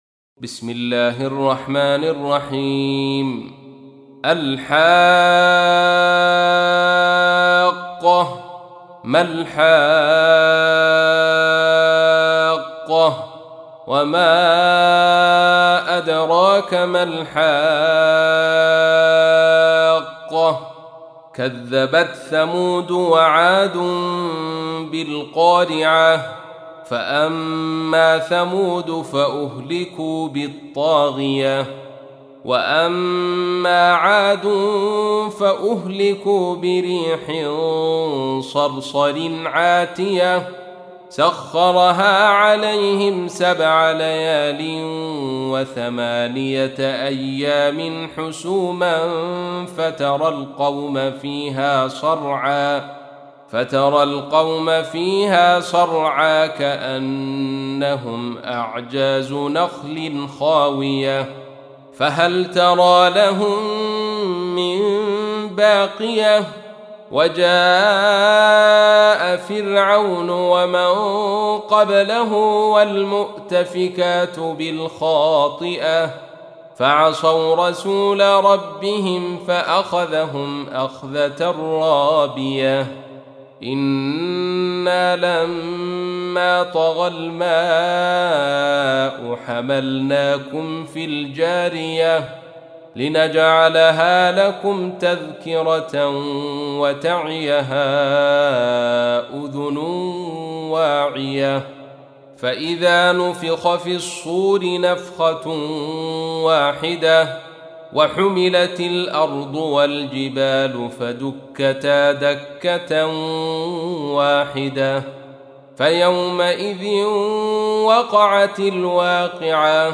تحميل : 69. سورة الحاقة / القارئ عبد الرشيد صوفي / القرآن الكريم / موقع يا حسين